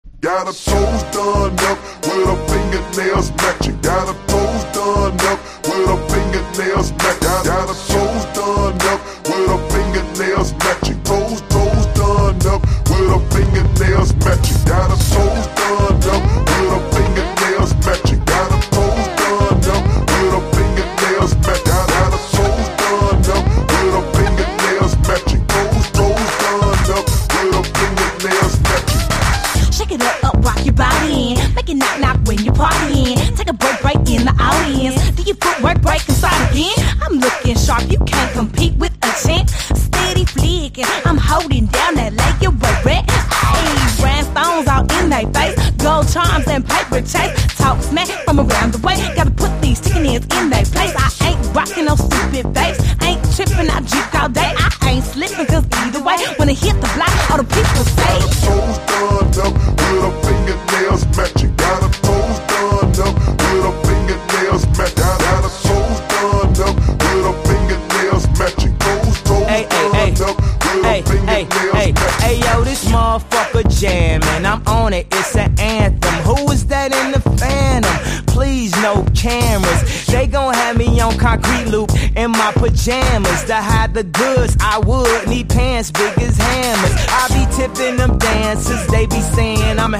BREAK BEATS / BIG BEAT